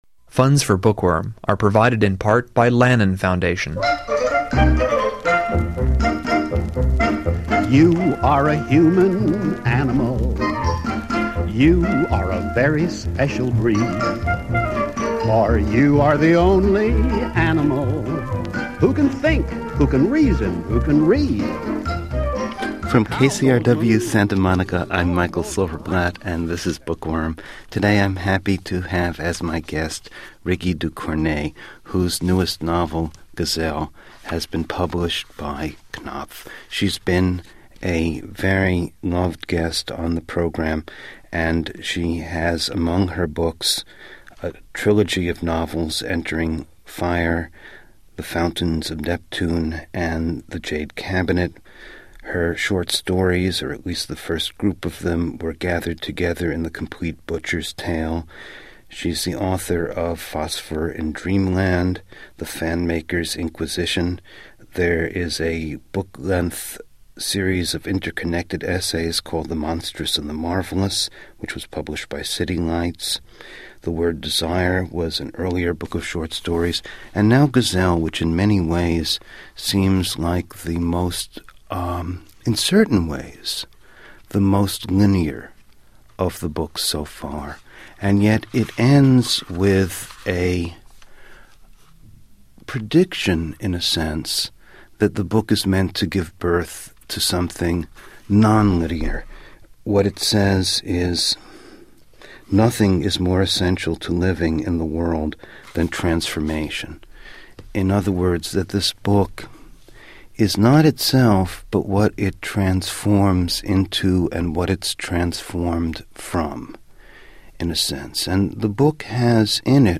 (Note: This interview will be pre-empted on KCRW by special programming.)